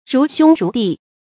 如兄如弟 注音： ㄖㄨˊ ㄒㄩㄥ ㄖㄨˊ ㄉㄧˋ 讀音讀法： 意思解釋： 情如兄弟。